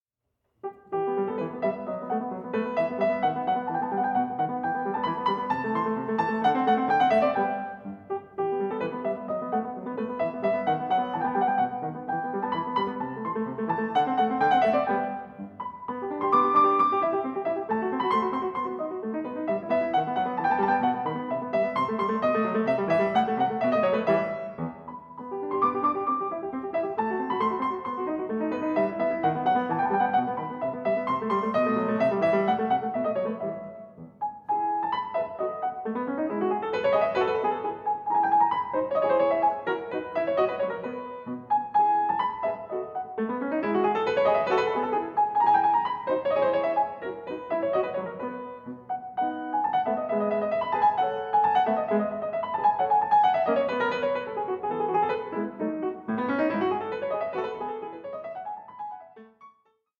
Adagio 4:11